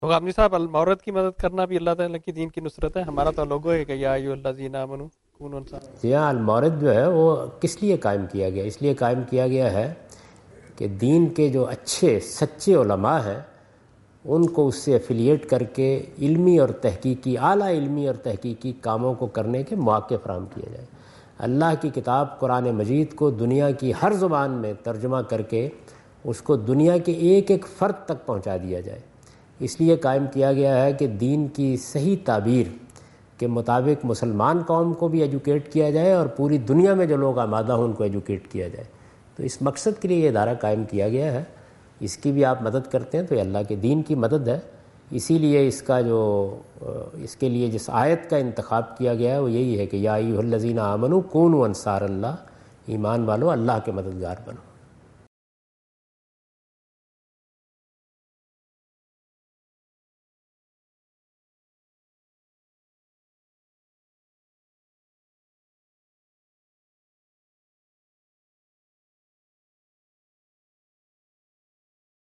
Javed Ahmad Ghamidi answer the question about "Helping Al-Mawrid, a Religious Service" in Macquarie Theatre, Macquarie University, Sydney Australia on 04th October 2015.
جاوید احمد غامدی اپنے دورہ آسٹریلیا کے دوران سڈنی میں میکوری یونیورسٹی میں "کیا المورد کی مدد کرنا بھی اللہ کے دین کی مدد ہے؟" سے متعلق ایک سوال کا جواب دے رہے ہیں۔